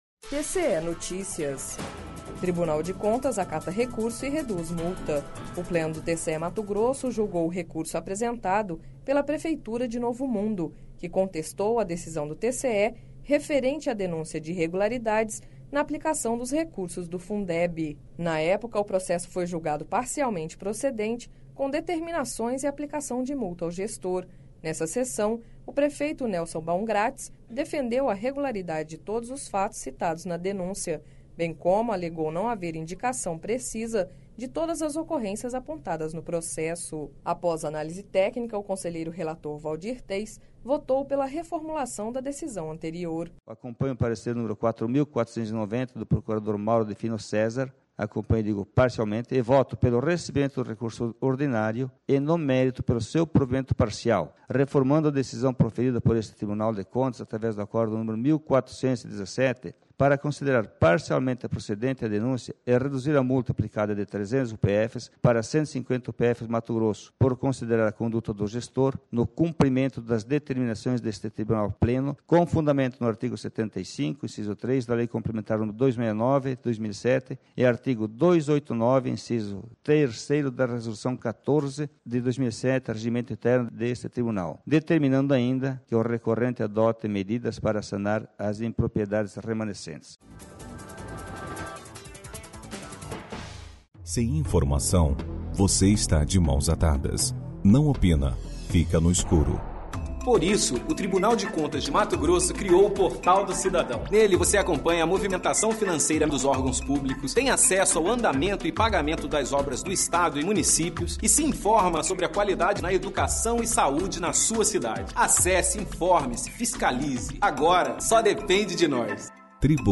Sonora: Waldir Teis - conselheiro do TCE-MT